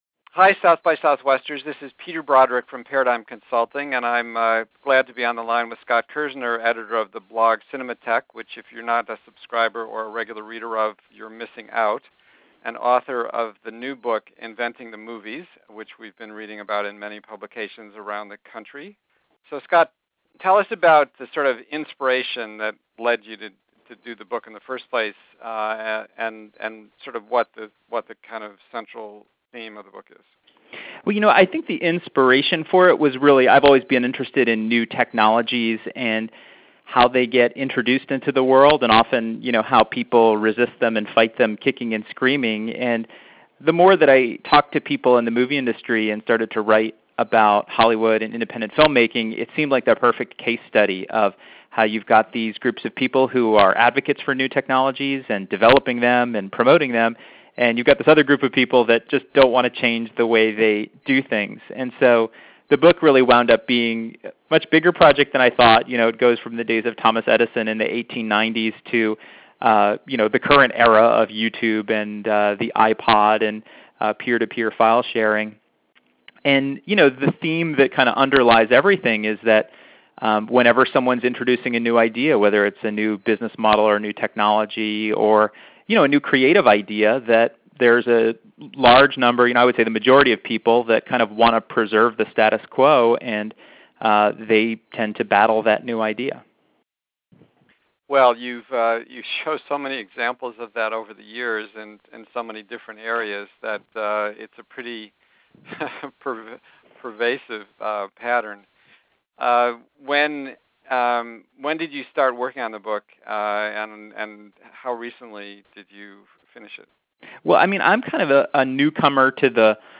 Podcast Conversation